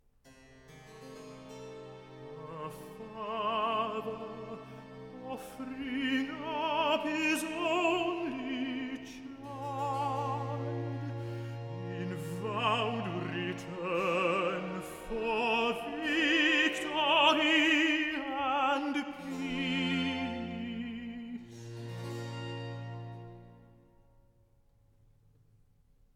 Accompagnato